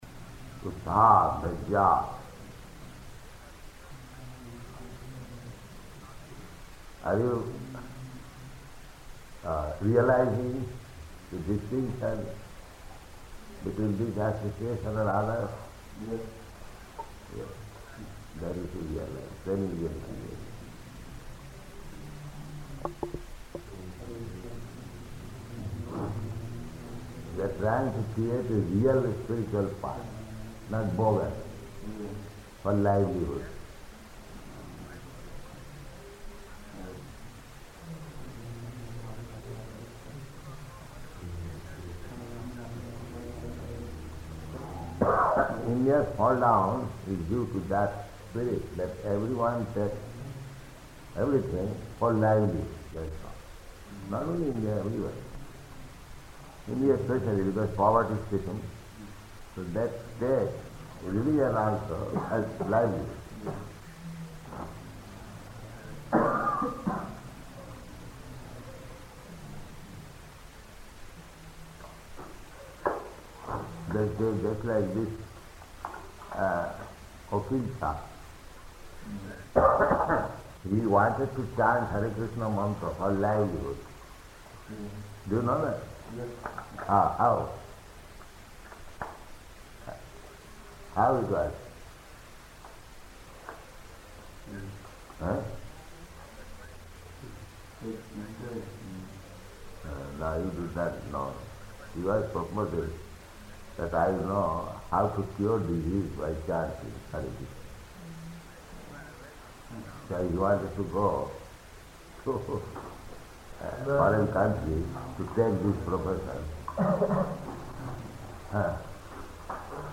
Room Conversation
Room Conversation --:-- --:-- Type: Conversation Dated: December 14th 1970 Location: Indore Audio file: 701214R1-INDORE.mp3 Prabhupāda: Utsāhan, dhairyāt.